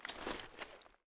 m1a1_new_draw.ogg